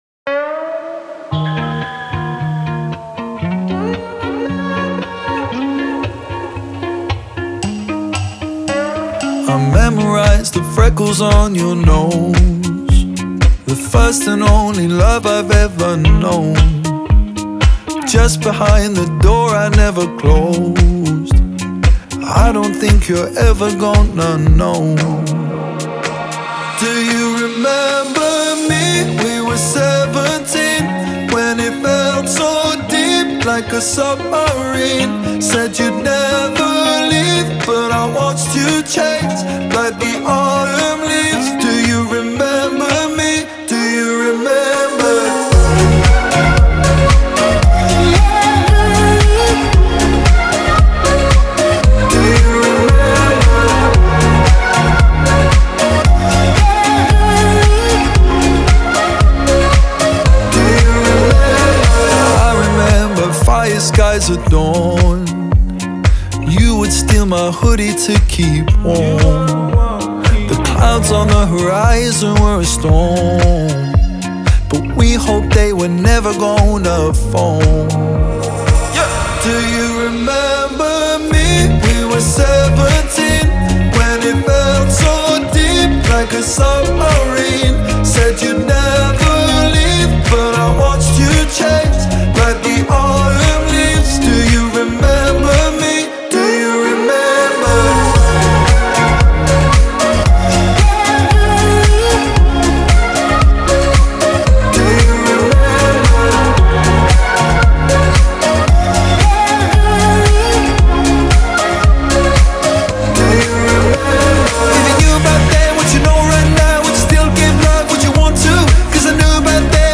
آهنگ پاپ